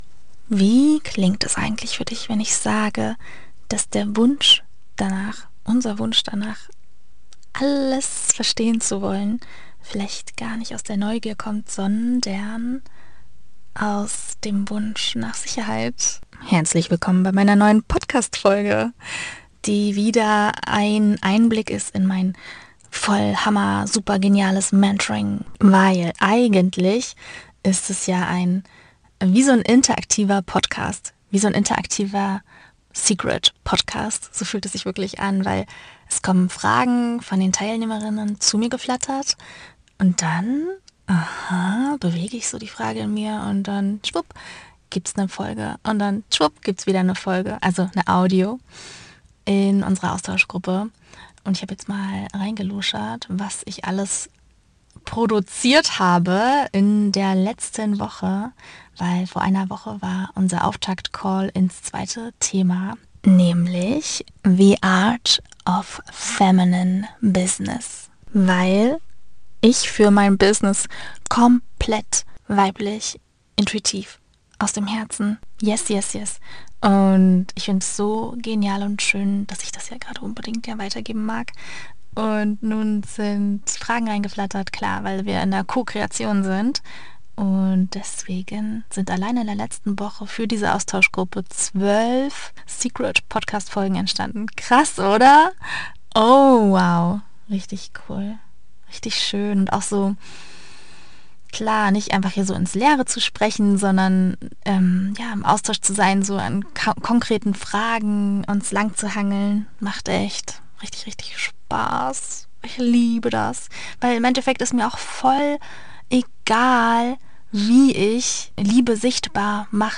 In dieser Folge nehme ich dich mit in einen tiefen Power-Talk, der mir beim Autofahren direkt aus dem Herzen kam. Es geht um das liebevolle Verhältnis zu unserem Verstand, um die Angst, sich zu zeigen und um die Magie, die entsteht, wenn wir unsere Wahrheit spüren und ihr einfach folgen.